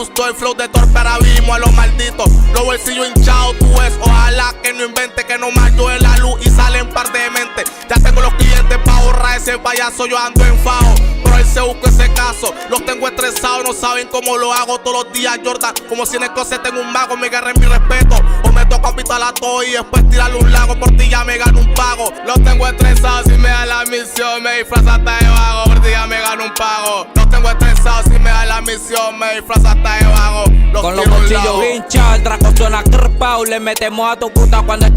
Latin Rap Hip-Hop Rap
Жанр: Хип-Хоп / Рэп